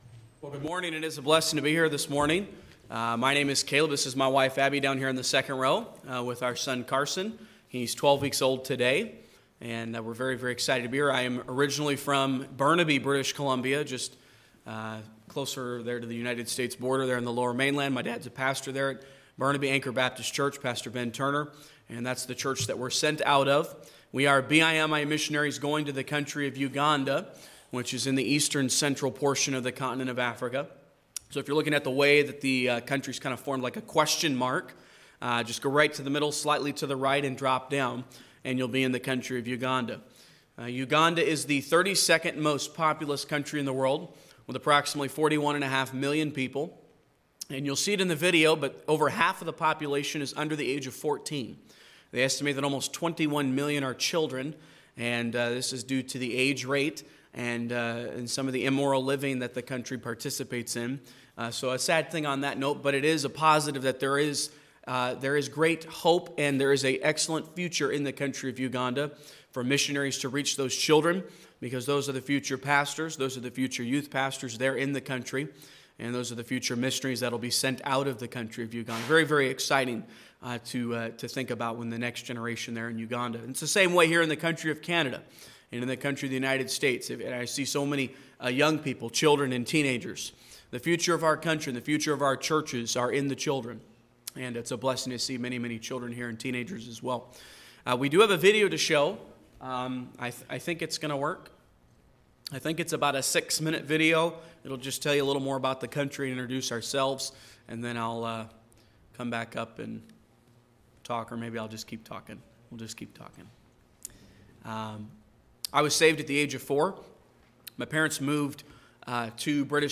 Genre: Preaching.
Service Type: Adult Sunday School